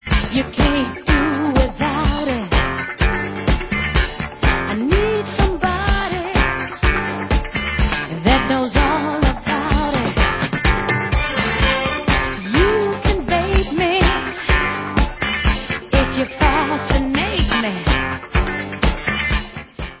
Dance/Soul